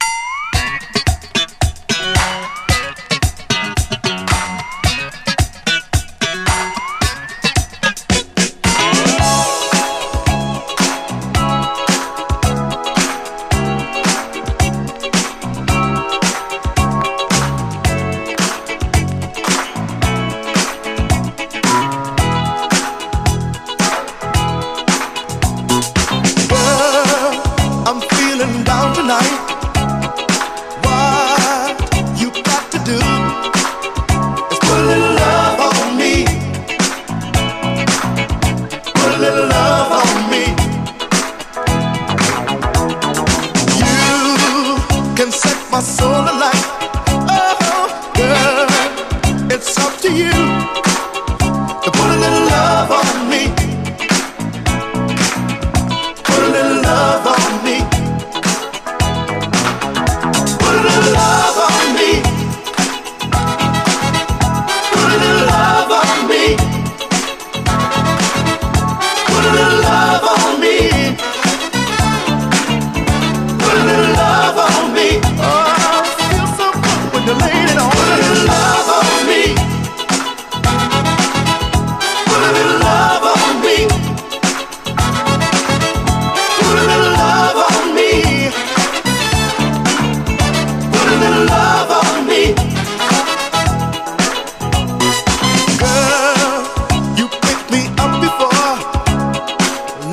SOUL, 70's～ SOUL, 7INCH
UK産70’Sミディアム・ダンサー！